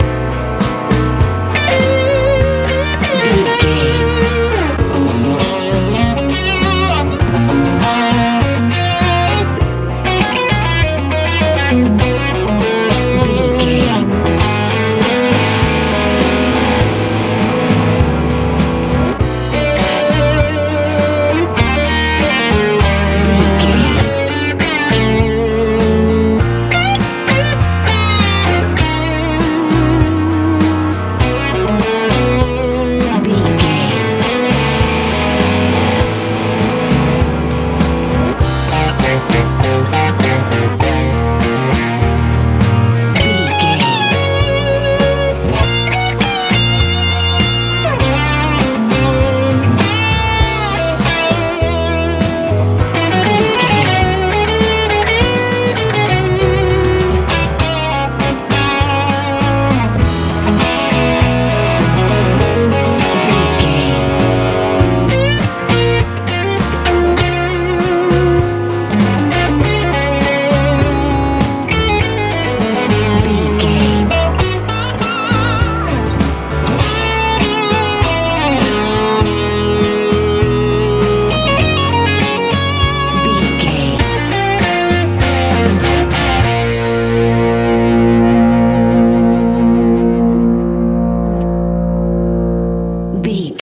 Ionian/Major
magical
hypnotic
intense
piano
drums
bass guitar
electric guitar
positive
proud